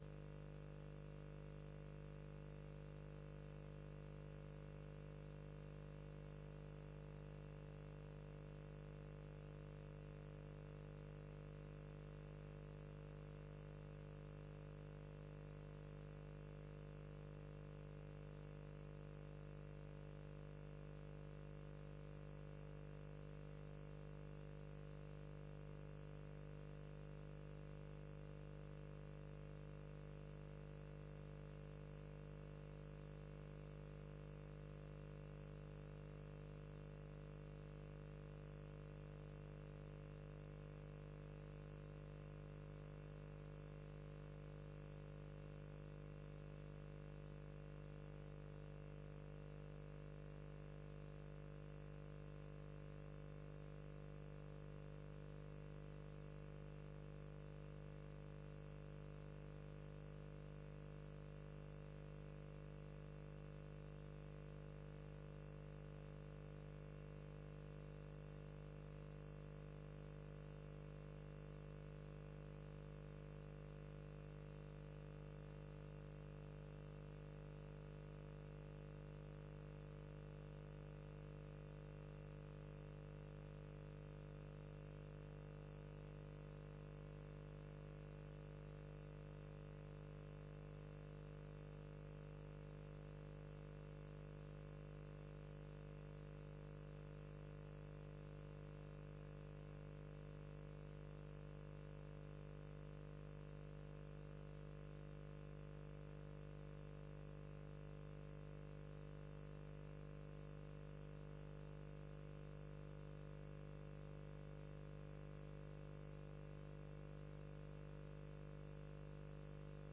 Download de volledige audio van deze vergadering
Locatie: Raadzaal
Raadssessie: Informatieve vragen en overleg gemeenteraad-college